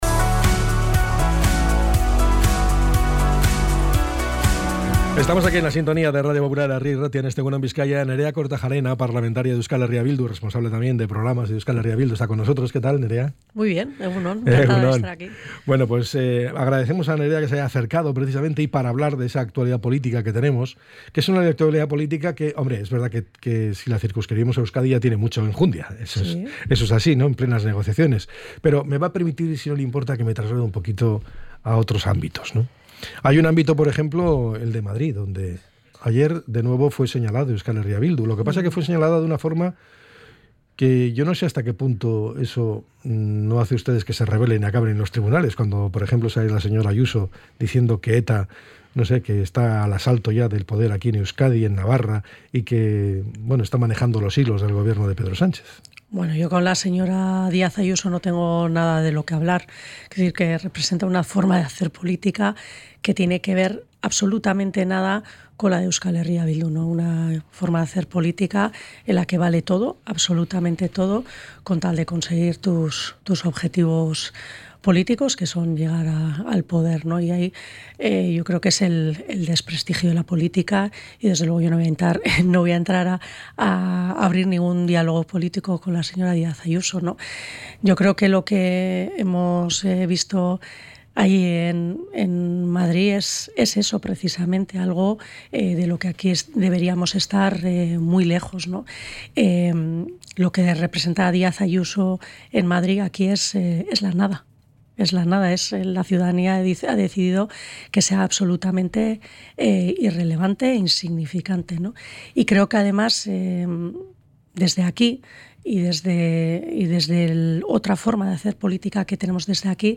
ENTREV.-NEREA-KORTAJARENA.mp3